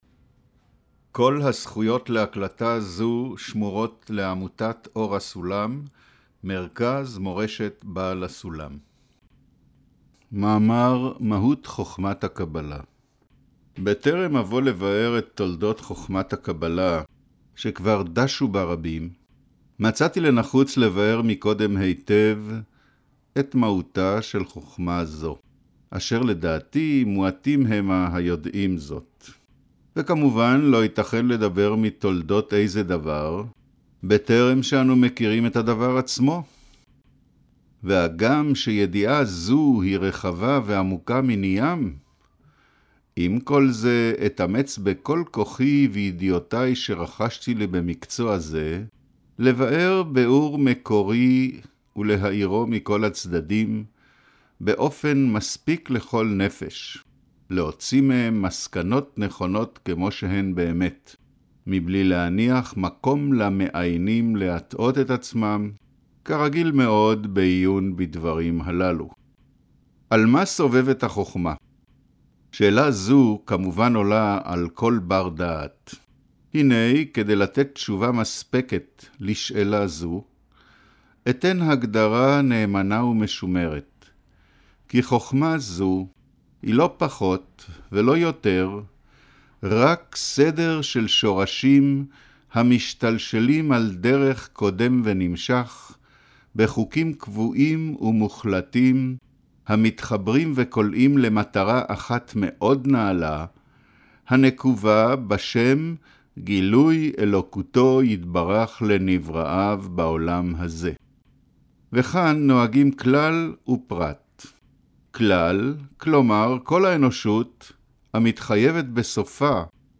אודיו - קריינות מהותה של חכמת הקבלה